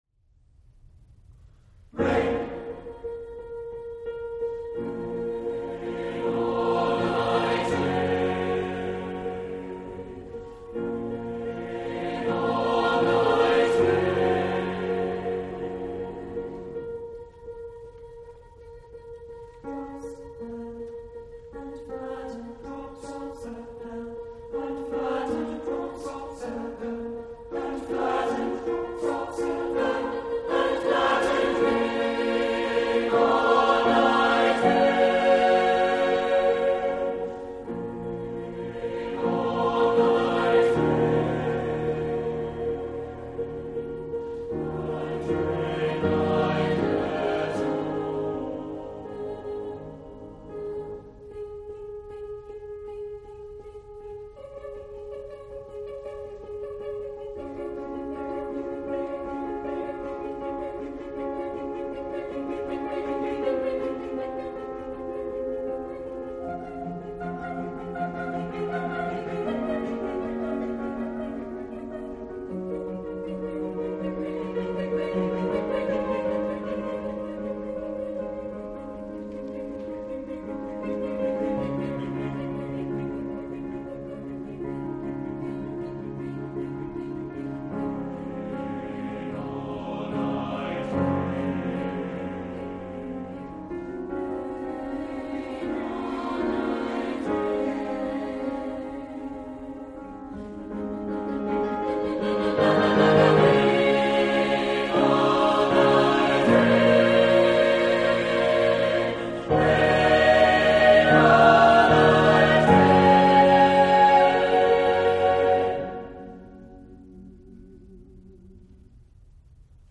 Voicing: SATB divisi and Piano